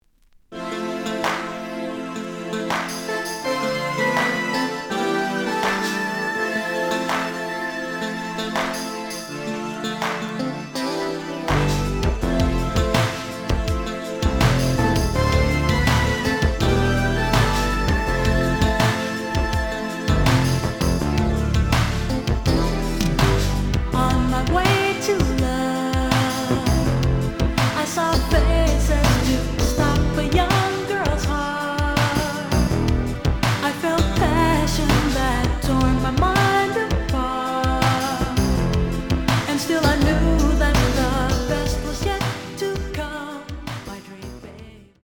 The audio sample is recorded from the actual item.
●Genre: Disco
Slight edge warp.